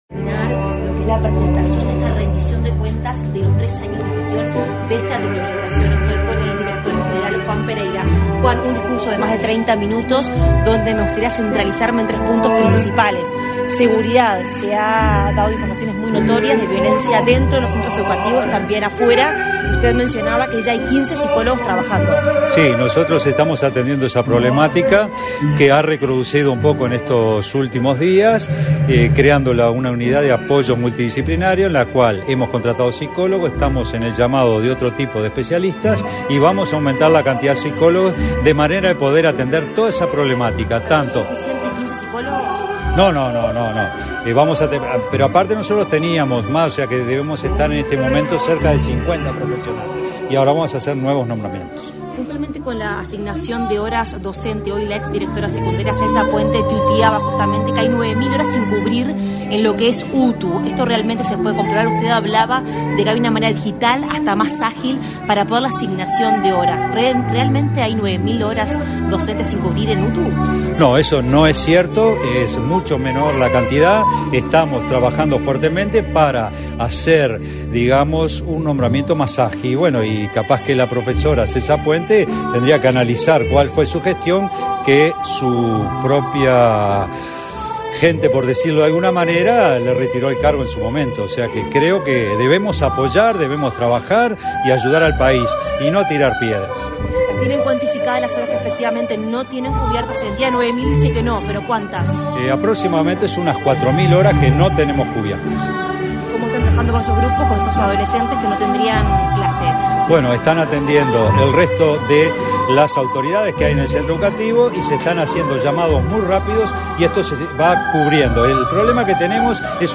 Declaraciones a la prensa del director general de la UTU, Juan Pereyra
Declaraciones a la prensa del director general de la UTU, Juan Pereyra 27/06/2023 Compartir Facebook X Copiar enlace WhatsApp LinkedIn La Dirección General de Educación Técnico Profesional (UTU) realizó, este 26 de junio, la presentación oficial de "Esta es la nueva UTU", a los tres años de inicio de la gestión. Tras el evento el director general de la UTU, Juan Pereyra, realizó declaraciones a la prensa.